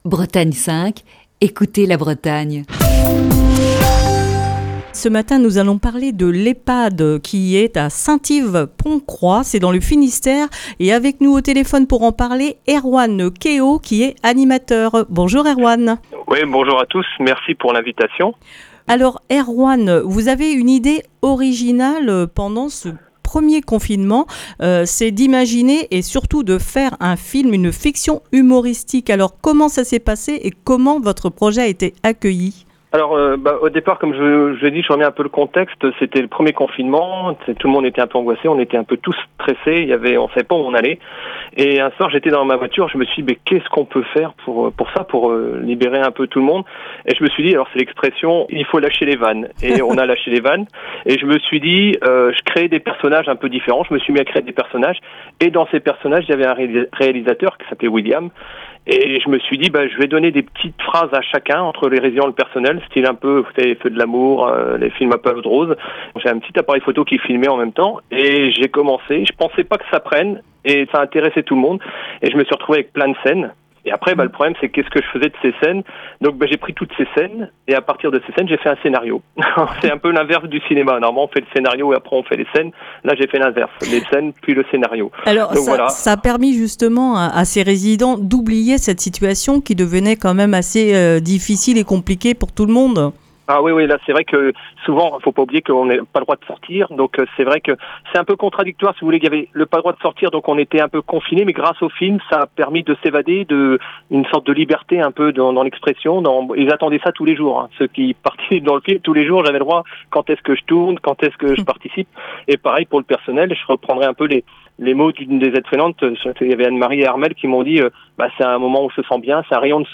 Ce mardi dans le coup de fil du matin